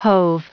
Prononciation du mot hove en anglais (fichier audio)
Prononciation du mot : hove